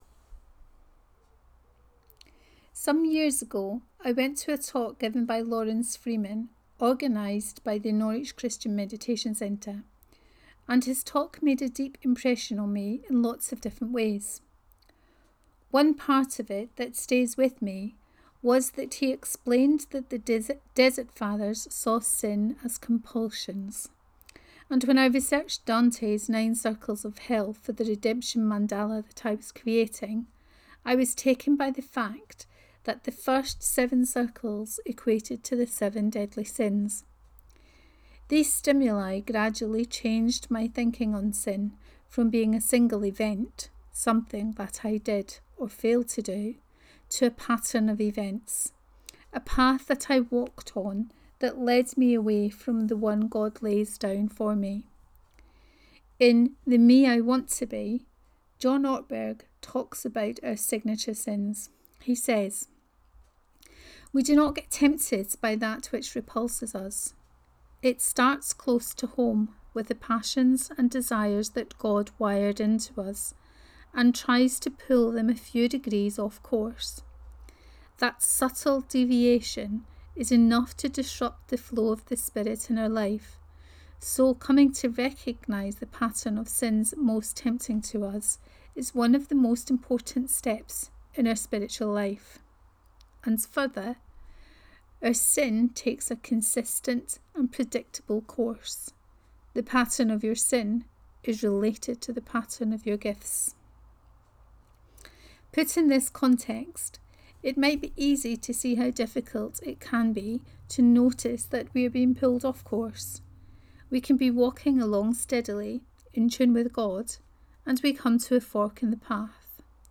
Step 1 and The Spiritual Exercises 2: Reading of this post